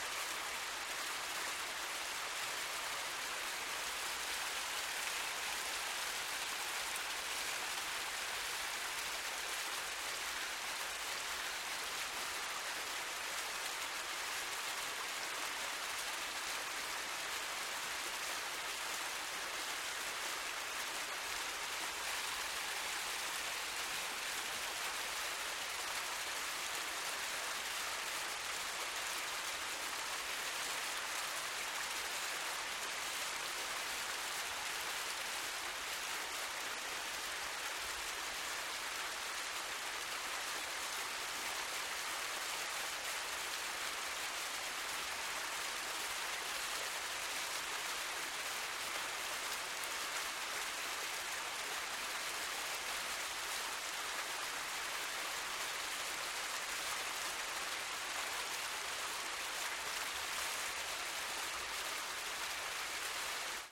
waterfall-sound